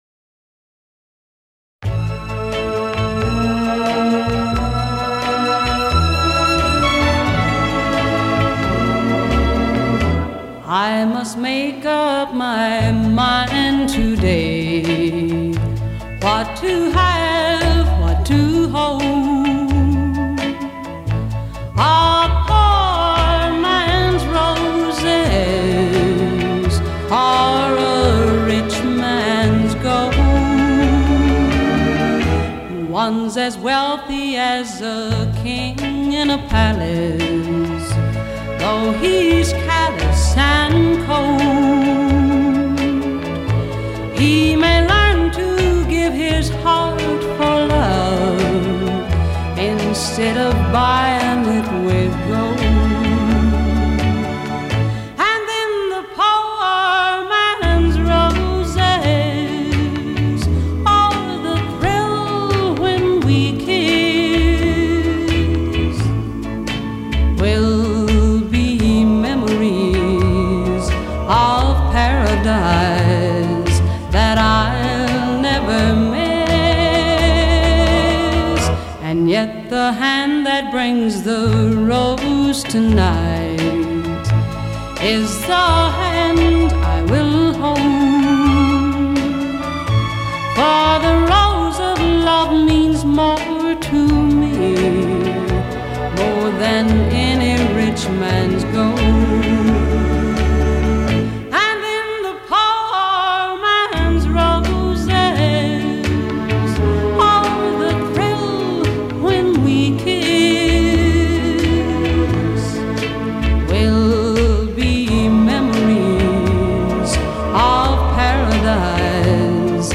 прекрасной певицей
и её весьма приятными и мелодичными композициями.